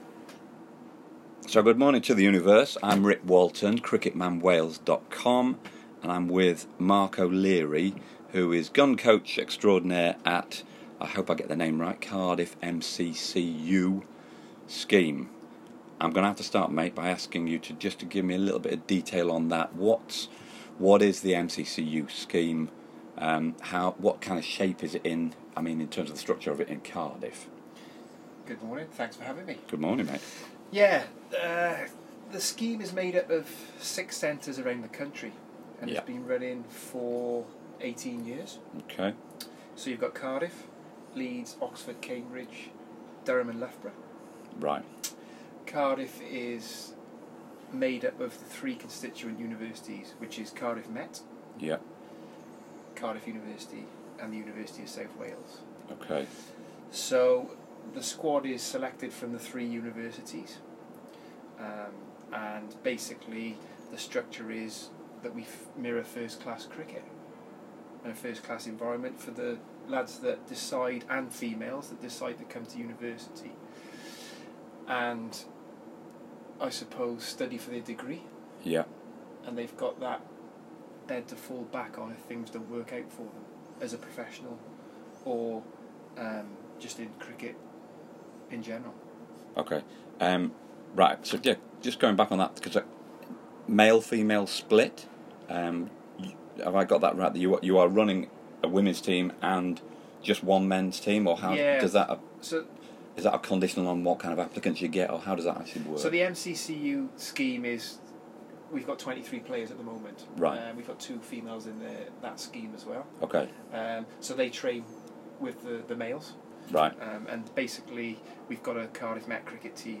I like the bloke; we talked.